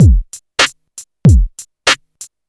• 96 Bpm '00s Electronic Drum Loop Sample G Key.wav
Free drum beat - kick tuned to the G note. Loudest frequency: 1275Hz
96-bpm-00s-electronic-drum-loop-sample-g-key-tuB.wav